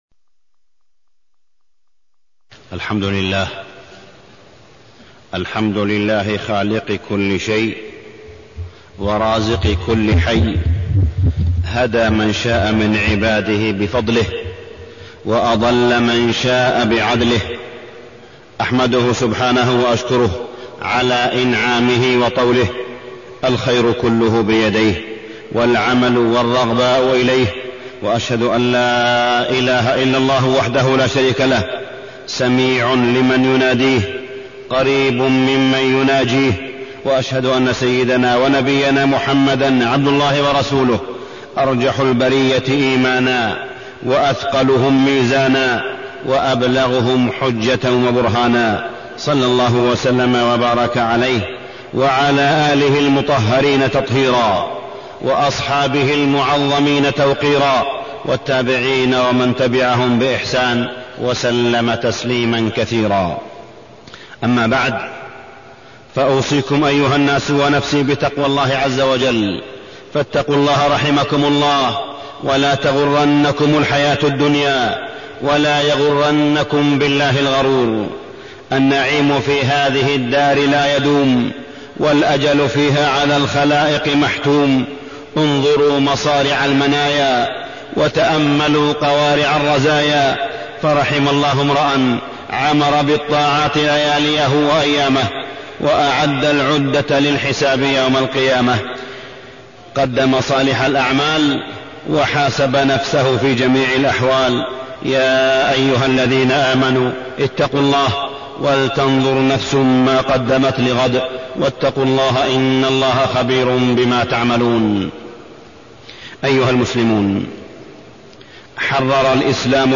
تاريخ النشر ٢٨ جمادى الآخرة ١٤٢٣ هـ المكان: المسجد الحرام الشيخ: معالي الشيخ أ.د. صالح بن عبدالله بن حميد معالي الشيخ أ.د. صالح بن عبدالله بن حميد عضل المرأة عن الزواج